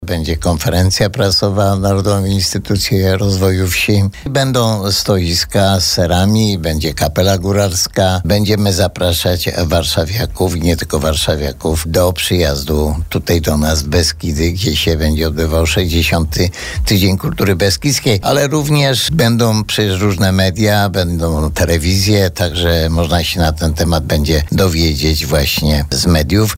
Mówi burmistrz Żywca Antoni Szlagor.